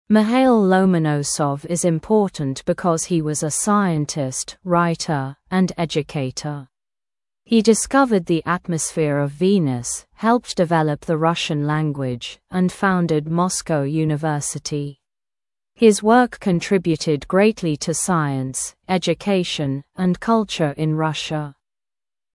[Михаил Ломоносов из импортэнт бикоз хи уоз э сайентист, райтэ энд эдьюкейтэ. Хи дискэвэрд зэ этмосфиэ ов винис, хэлпт дивелэп зэ рашн лэнгвич энд фаундэд москво юнивёсити. Хиз уёк кэнтрибьютид грэйтли ту сайэнс, эдьюкейшн энд калчэ ин раша.].